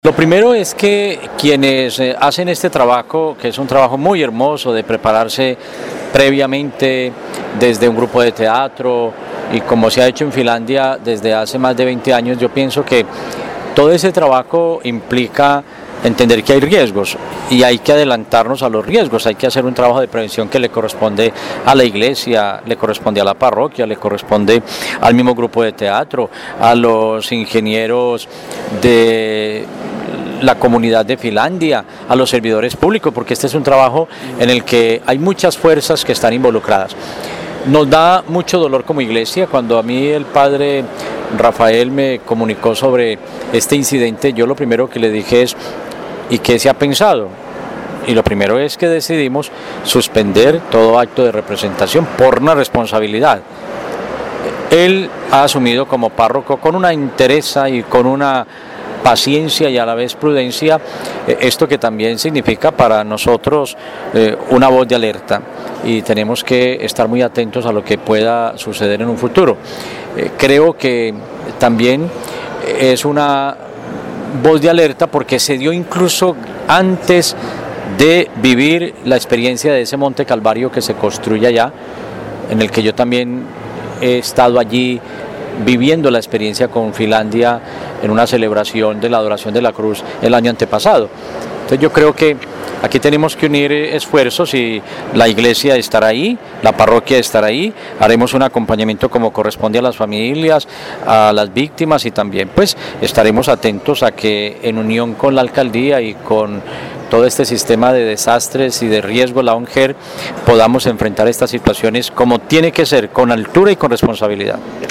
Monseñor, Carlos Arturo Quintero, Obispo de Armenia